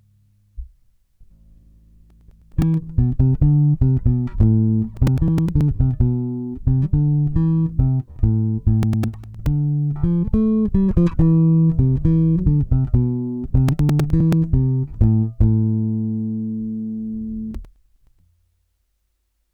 J'ai un petit problème quand j'enregistre ma basse. J'ai des petits craquements sur l'enregistrement.
Carte Son : M-Audio Mobile Pre USB
Et ca me fait toujours ca que j'enregistre ma basse directement ou en passant par l'ampli.